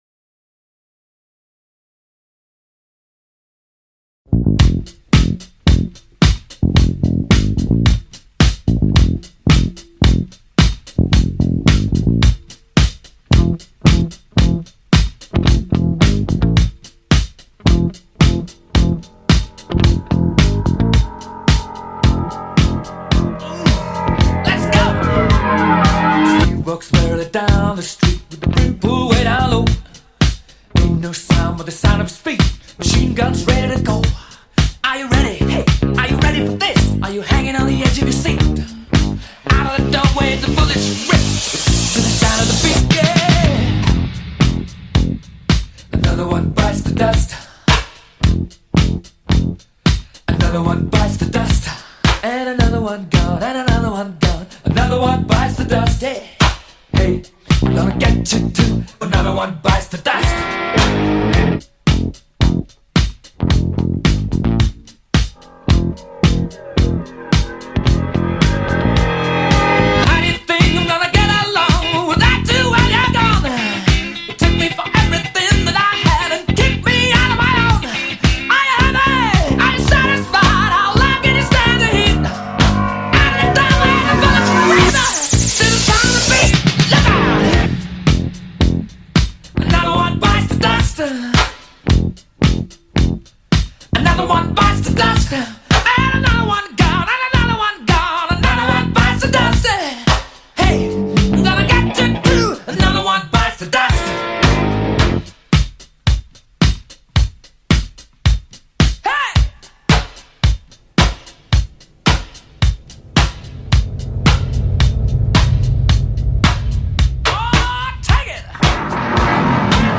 Classic Rock / Live Performance